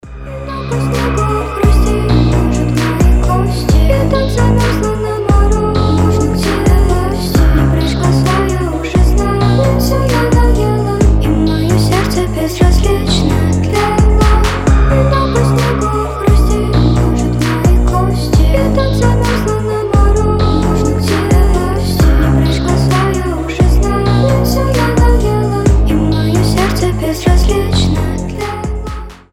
a liitle bit speed up